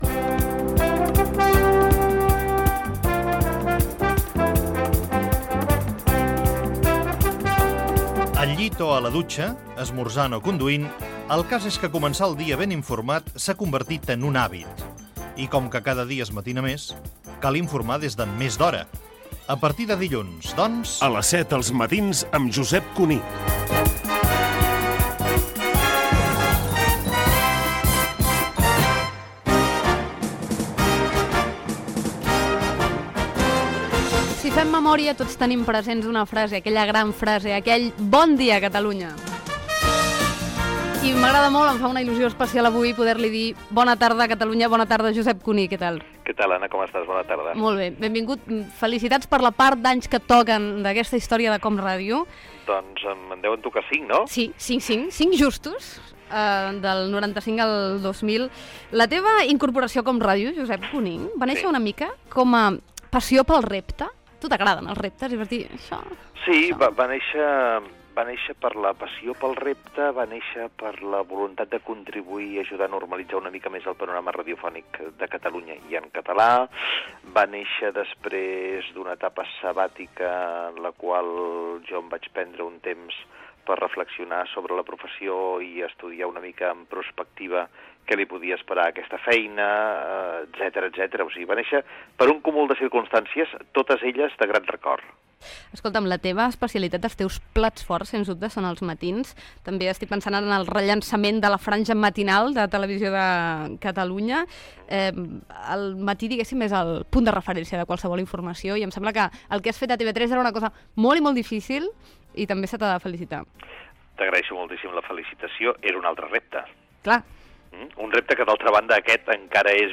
Promoció de "Els matins amb Josep Cuní". Entrevista telefònica al seu presentador, Josep Cuní.